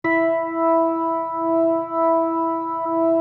B3LESLIE E 5.wav